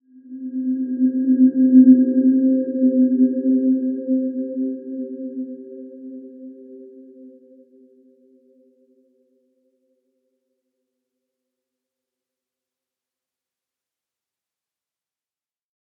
Dreamy-Fifths-C4-p.wav